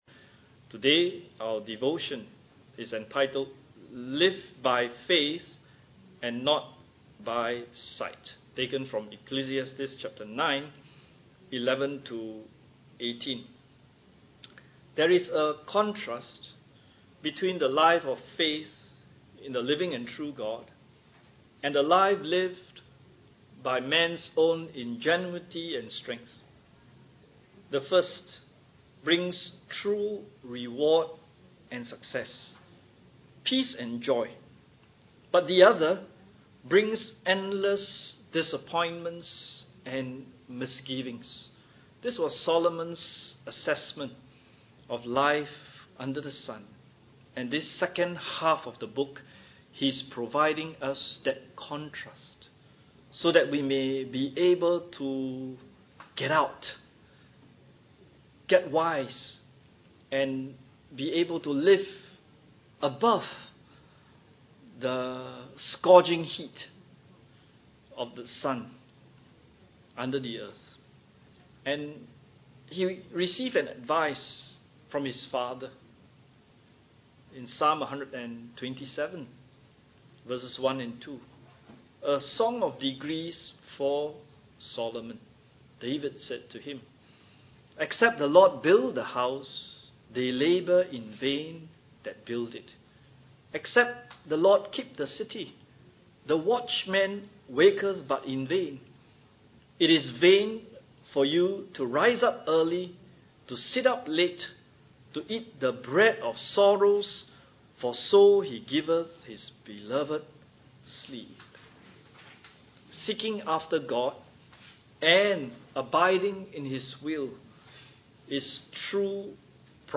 Study of the Book of Ecclesiastes